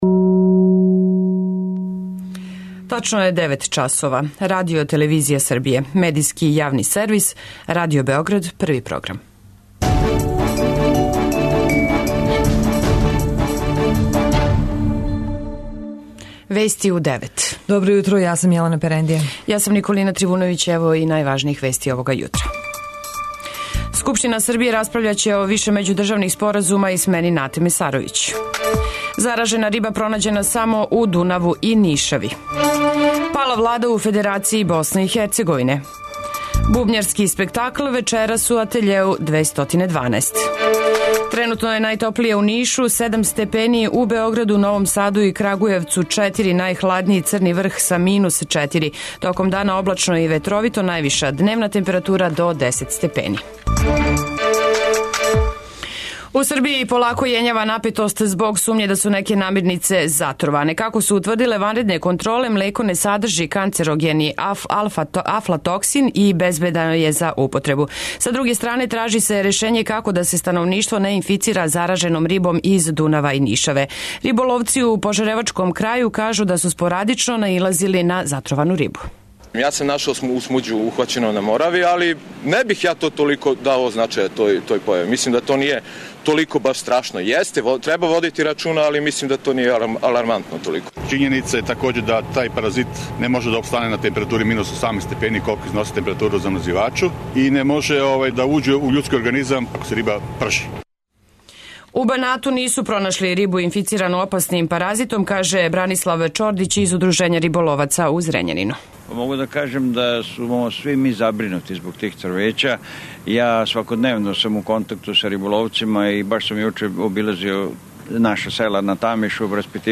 Уреднице и водитељке